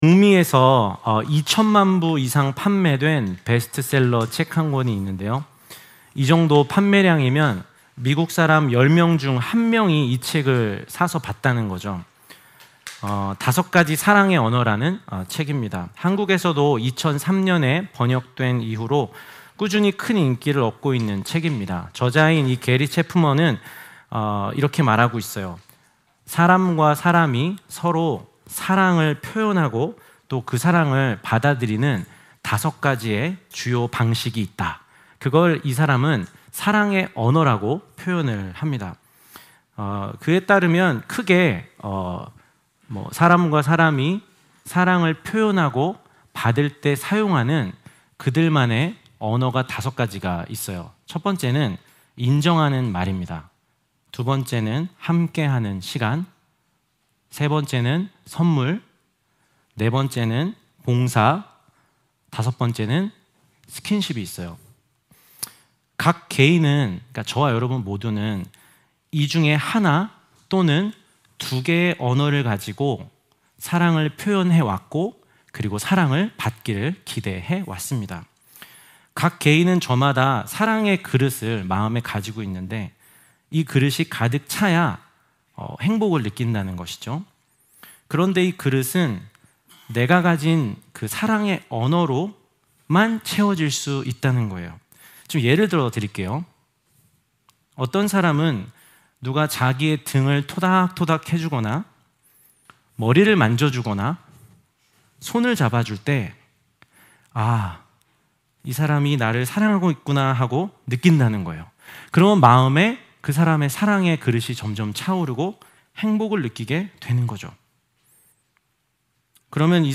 주일예배, 수요예배 설교를 매주 내려받으실 수 있습니다.